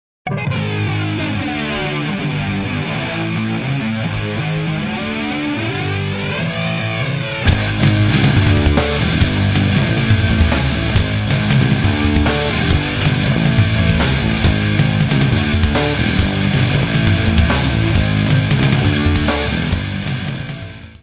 voz
baixo
bateria
guitarra
: stereo; 12 cm
Área:  Pop / Rock